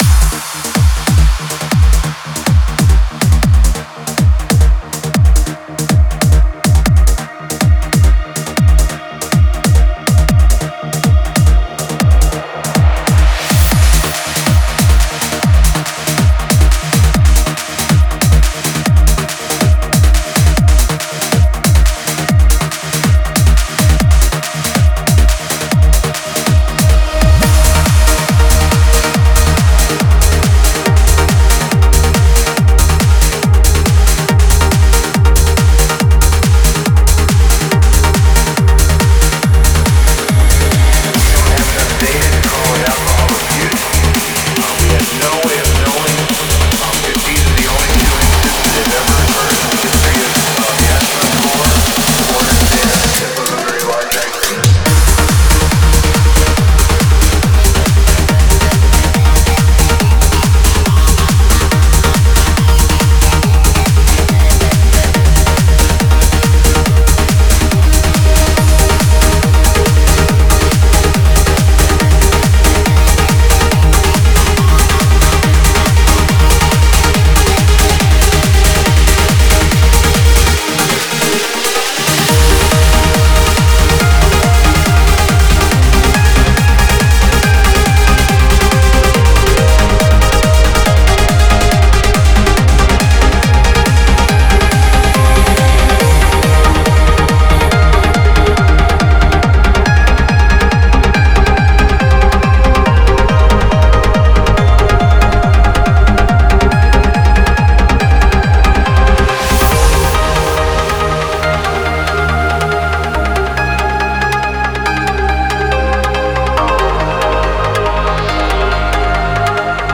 Стиль: Progressive Trance